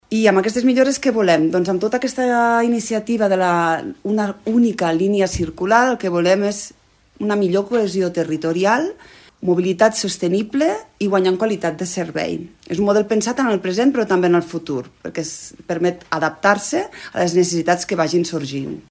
Ho ha dit la consellera de Comunicació i Gestió i Desenvolupament Humà, Meritxell Rabadà.